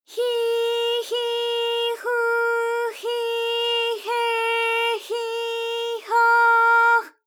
ALYS-DB-001-JPN - First Japanese UTAU vocal library of ALYS.
hi_hi_hu_hi_he_hi_ho_h.wav